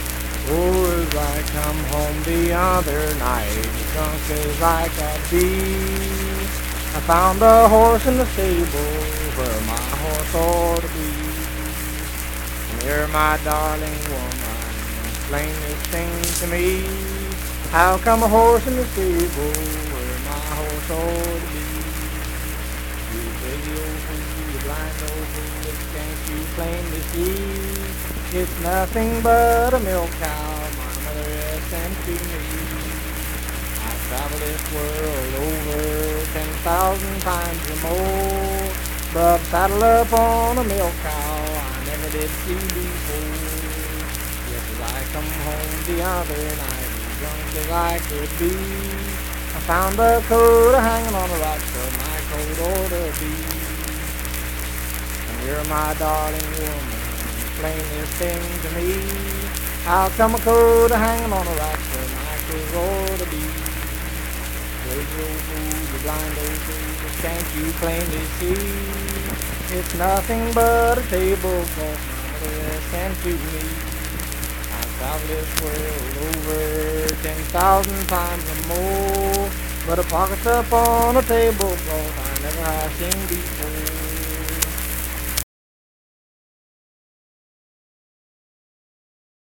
Unaccompanied vocal music
Voice (sung)
Pleasants County (W. Va.), Saint Marys (W. Va.)